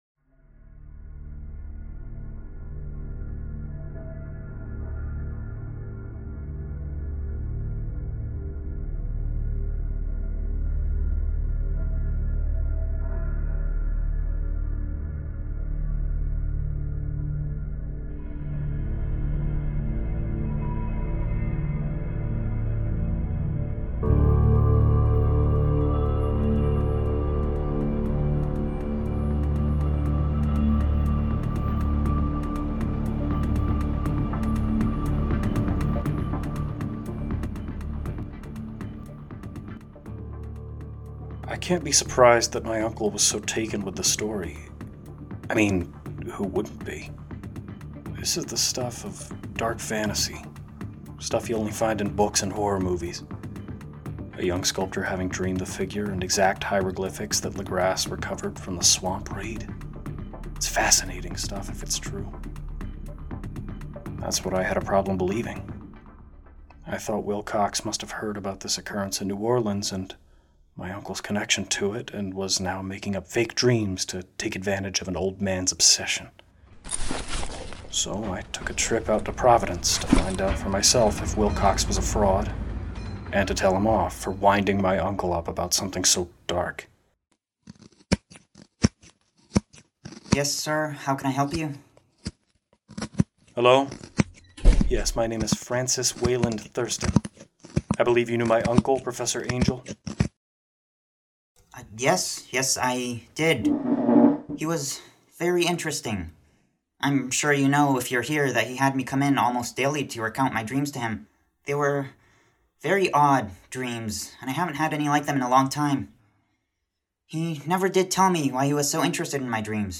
This is part two of our three-part audio drama adaptation of H.P. Lovecraft's "The Call of Cthulhu"!